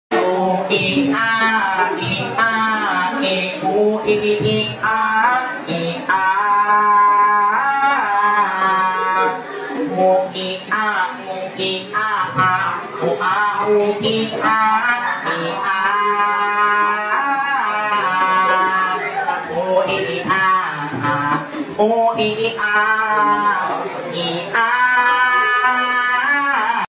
Guy Sings OIIA Cat
Guy-Sings-OIIA-Cat-.mp3